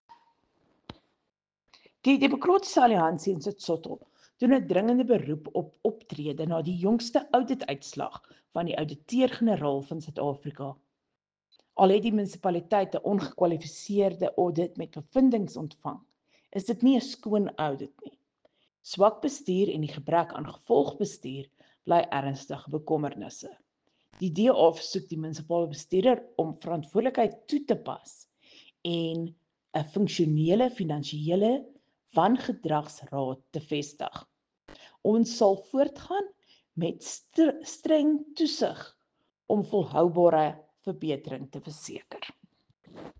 Afrikaans soundbites by Cllr Riëtte Dell and Sesotho soundbite by Cllr Tim Mpakathe.